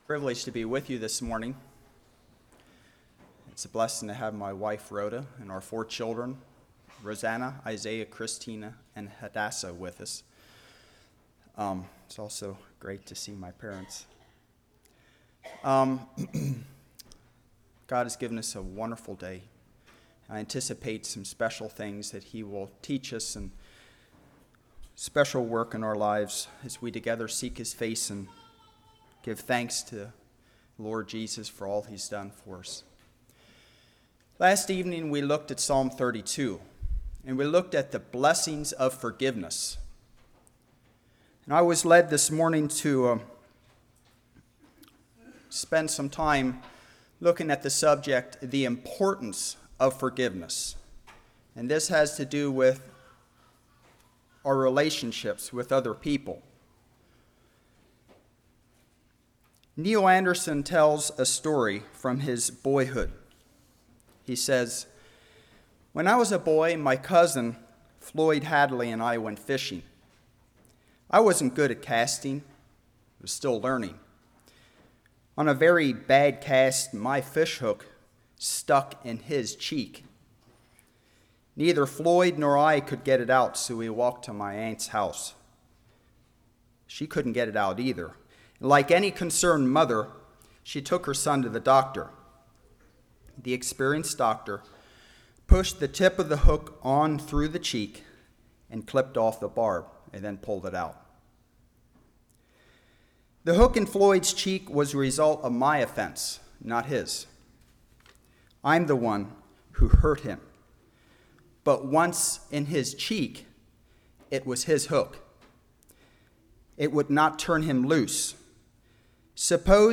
Series: Spring Lovefeast 2012
Service Type: Morning